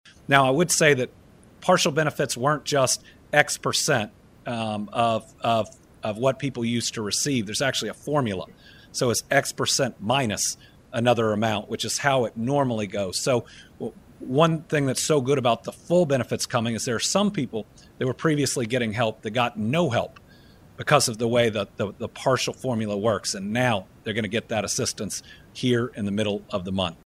Governor Andy Beshear addressed the impact of the recent federal government shutdown and updates on SNAP benefits during his Team Kentucky Update on Thursday.